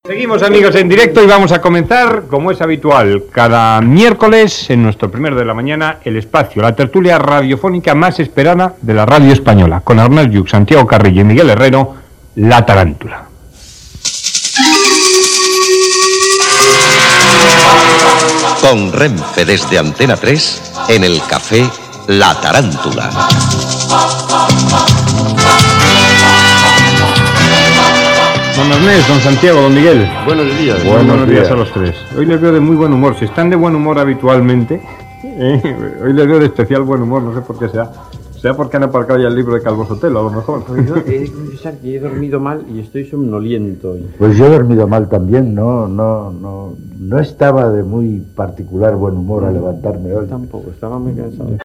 Inici de la tertúlia "La tarántula" amb Santiago Carrillo, Ernest Lluch y Miguel Herrero de Miñón.
Info-entreteniment